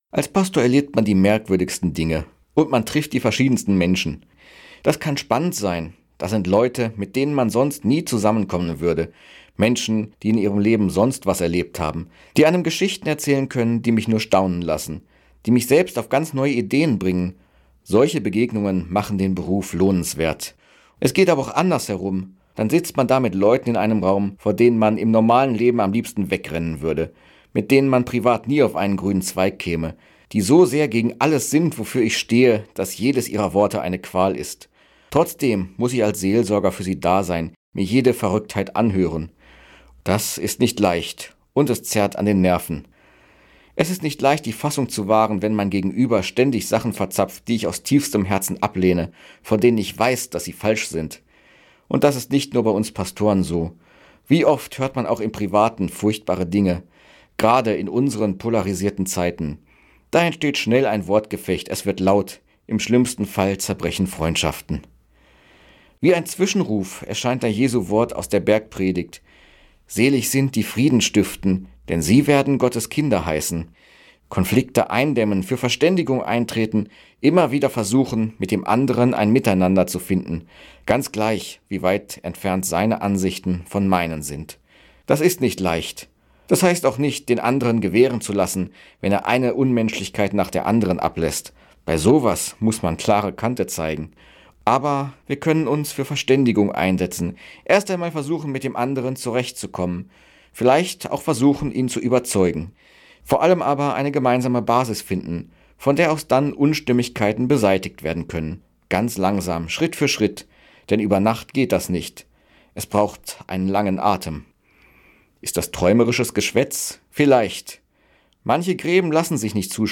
Radioandacht vom 31. Juli